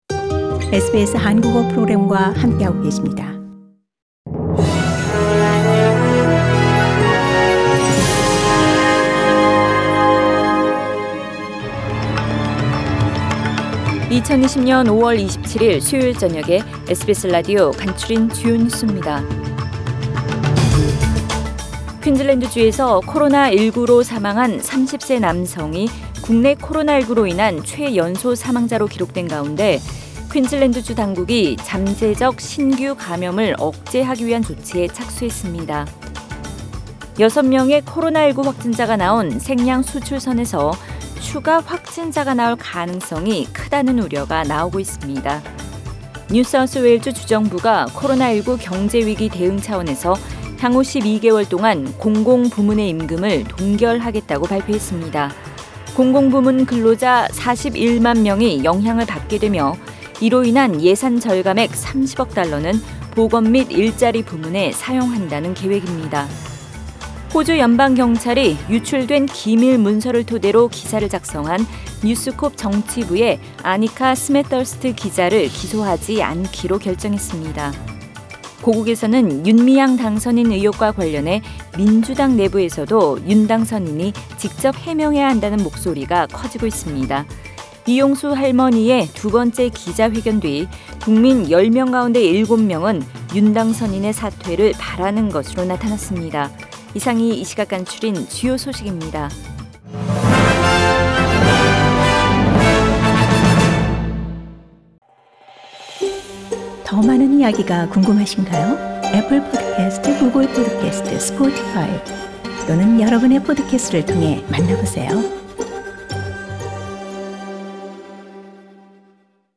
SBS 한국어 뉴스 간추린 주요 소식 – 5월 27일 수요일
2020년 5월 27일 수요일 저녁의 SBS Radio 한국어 뉴스 간추린 주요 소식을 팟 캐스트를 통해 접하시기 바랍니다.